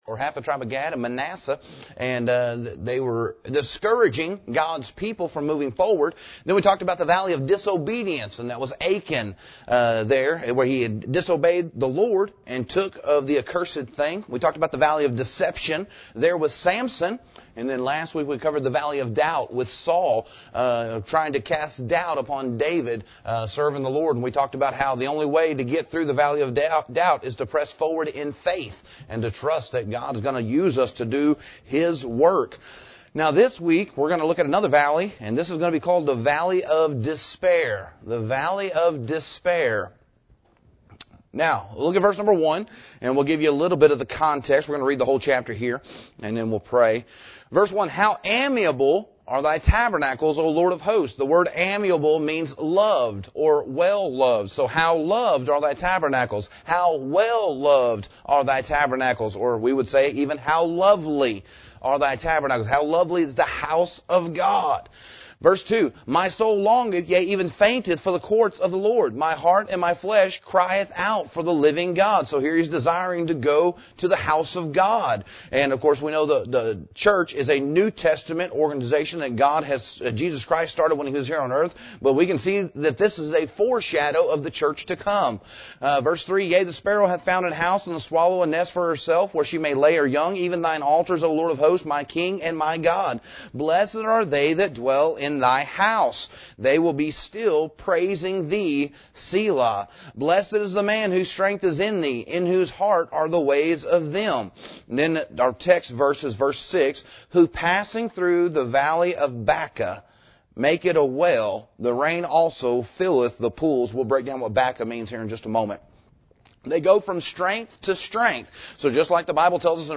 Valley of Baca – Cornerstone Baptist Church | McAlester, OK